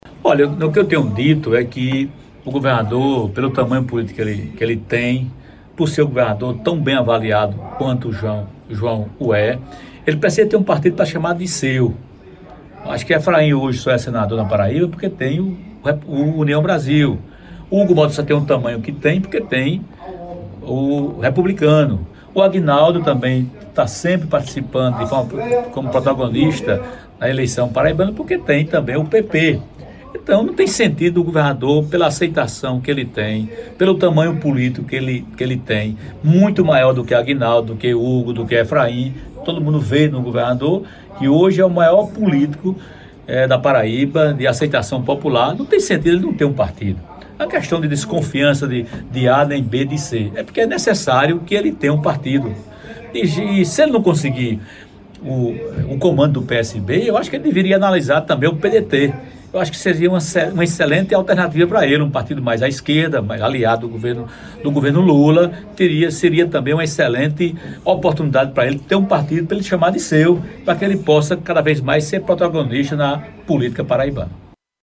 O comentário do parlamentar foram registrados pelo programa Correio Debate, da 98 FM, de João Pessoa, nesta quarta-feira (25/10).
Dep.-Adriano-Galdino-Presidente-ALPB.mp3